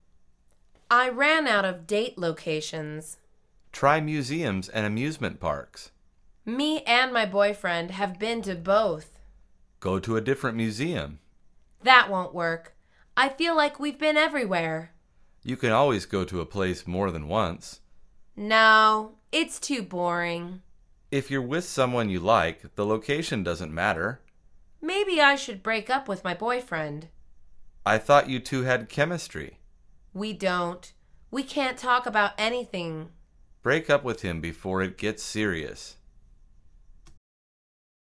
مجموعه مکالمات ساده و آسان انگلیسی – درس شماره هشتم از فصل دوستیابی: مکان های قرار